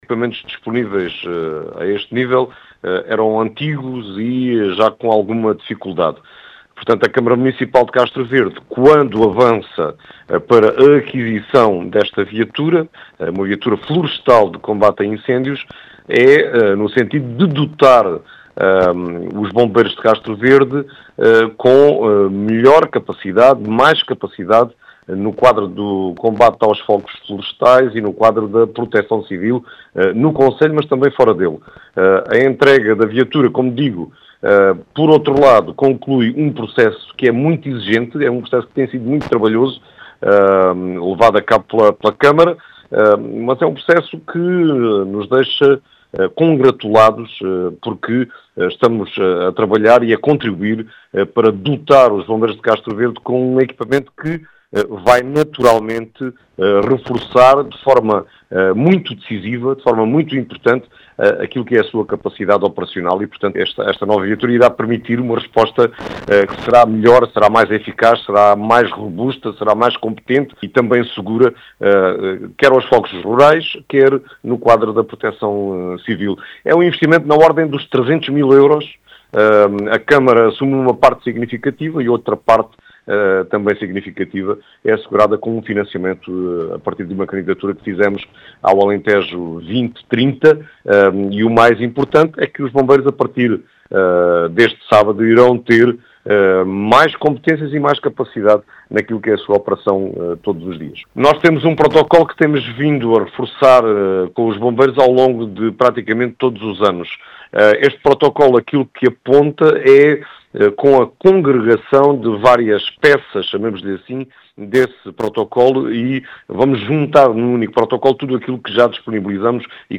As explicações são do presidente da Câmara Municipal de Castro Verde, António José Brito.